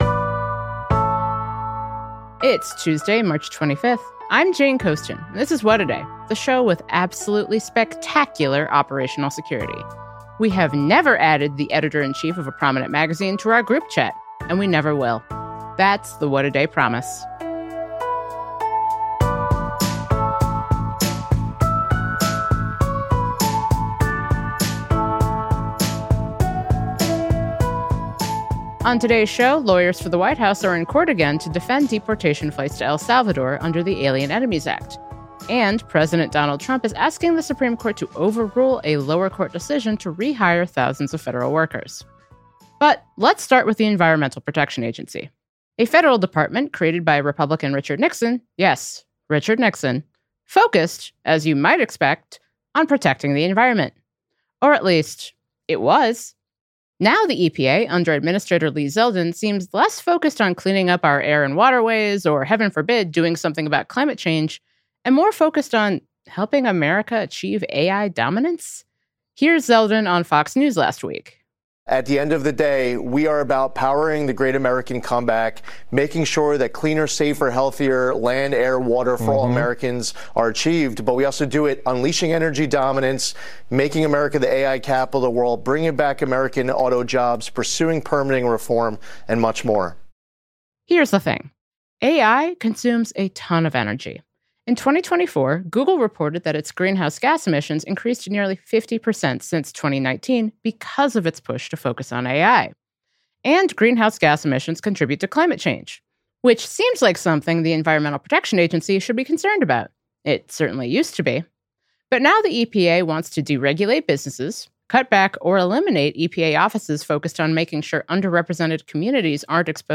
Former EPA Administrator and White House National Climate Advisor Gina McCarthy joins us to discuss how states and cities are stepping up to protect their communities and the planet.